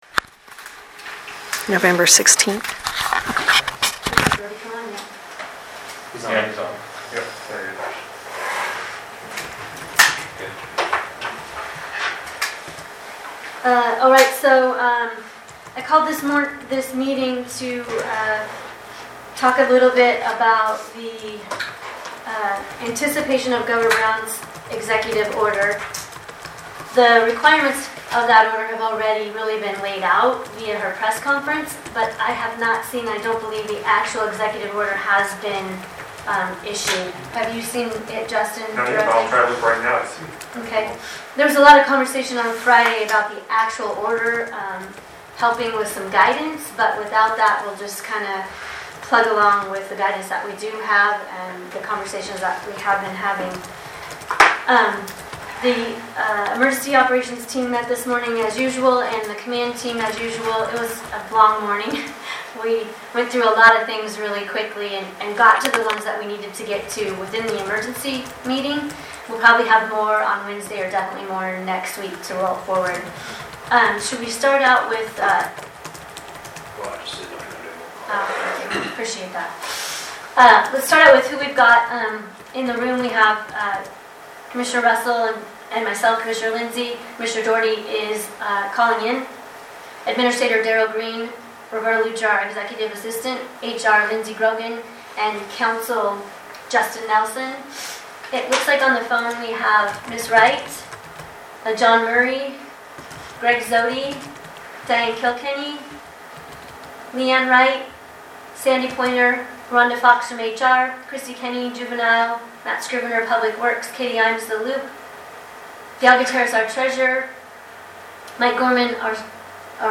Emergency Board of Commissioners Meeting | Morrow County Oregon